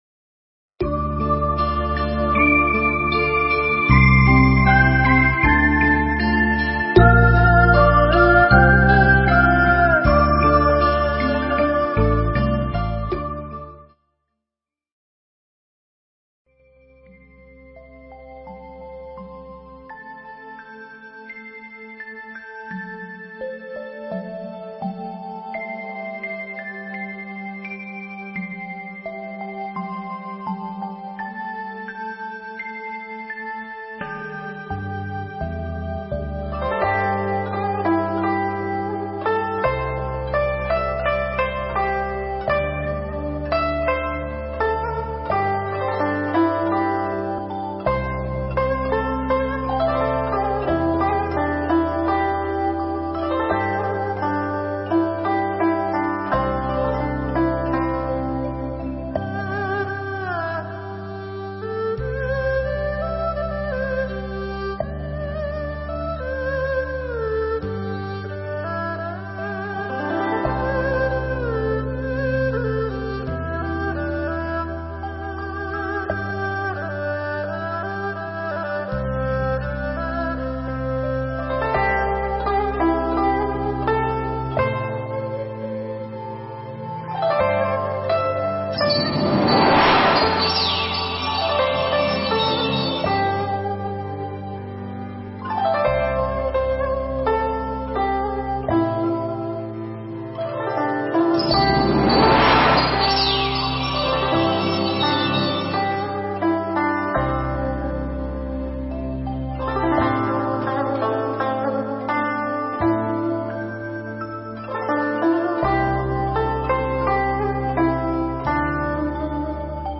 Nghe Mp3 thuyết pháp Vì Pháp Quên Bệnh